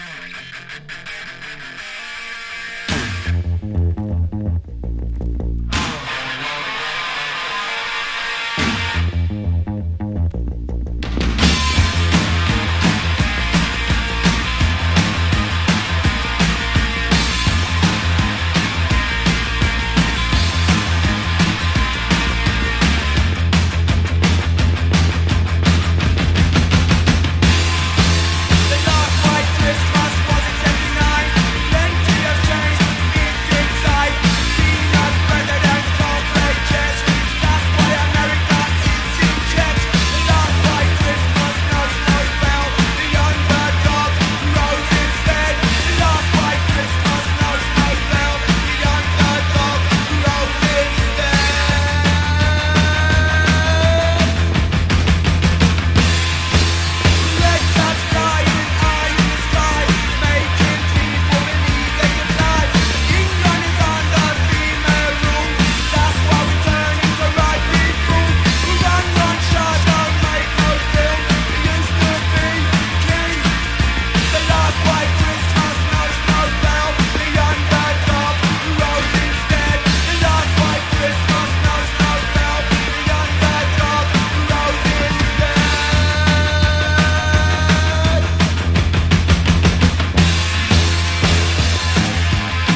社会的なテーマを訴えかけるポストパンク・ソング！